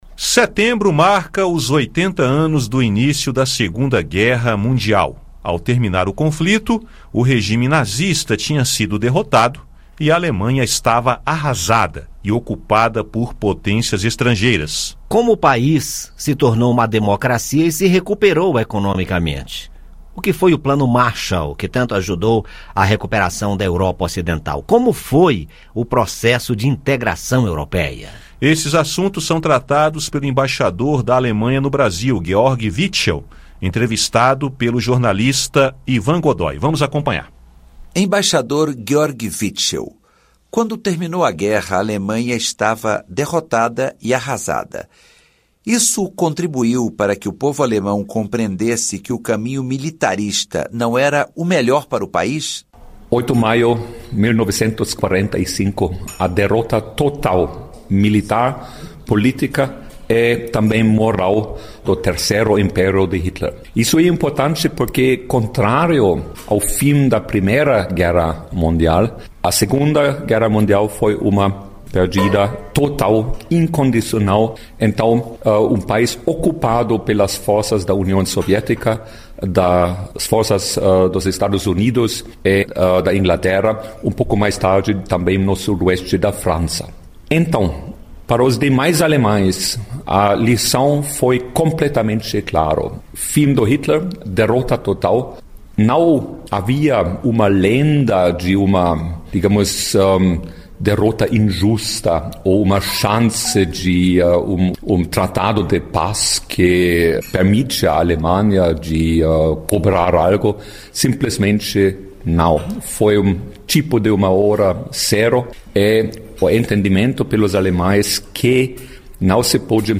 E nesta quinta-feira (12) conversamos com o embaixador da Alemanha no Brasil, Georg Witschel, sobre a recuperação alemã e a integração da Europa. Ouça o áudio com a entrevista.